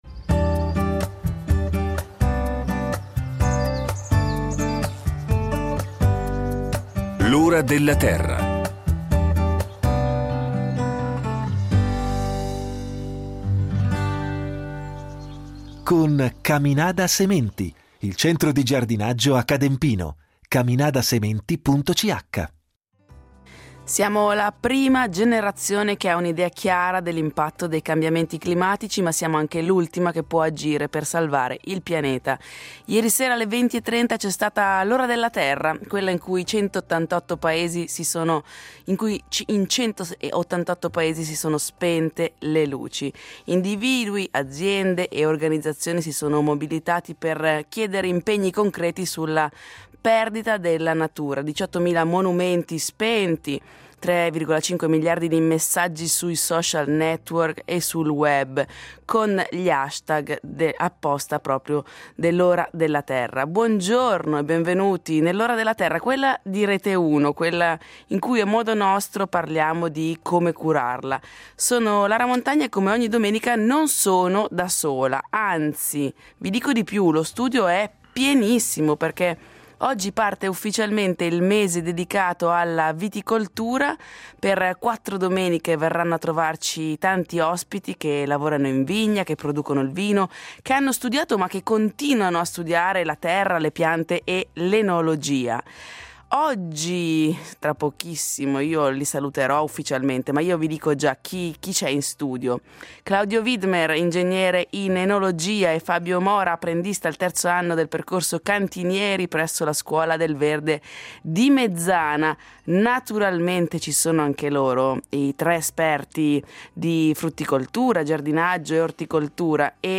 Presenti anche i tre esperti in studio per rispondere alle domande del pubblico da casa.